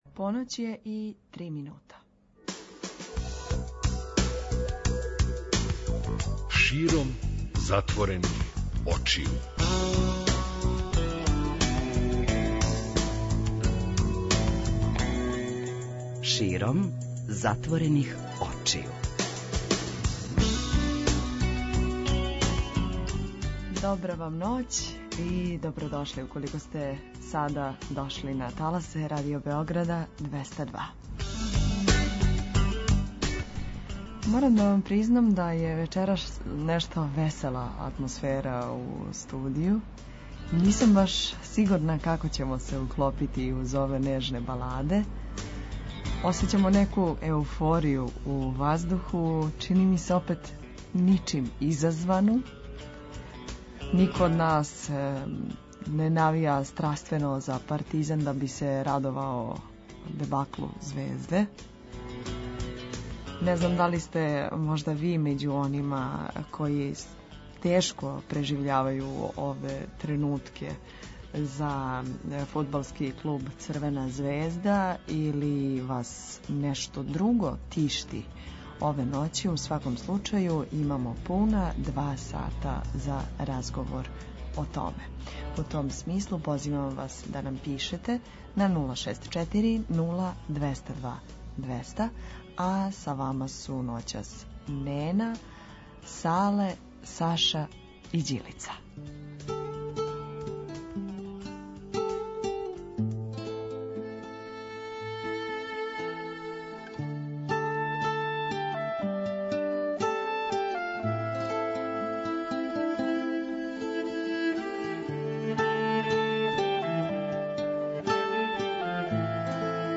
преузми : 54.63 MB Широм затворених очију Autor: Београд 202 Ноћни програм Београда 202 [ детаљније ] Све епизоде серијала Београд 202 Устанак Брза трака Брза трака: Млади у саобраћају Како сте спавали?